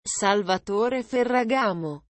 13 Audio Cara Mengucap Nama Brand Fashion Ternama. Sambil Belajar Bahasa Perancis dan Italia